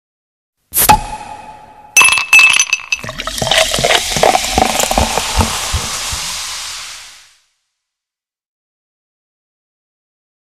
На этой странице собраны разнообразные звуки наливания воды и других жидкостей: от наполнения стакана до переливания напитков в высокий бокал.
Звук відкриття пляшки кока-коли та наливання у склянку з бульбашками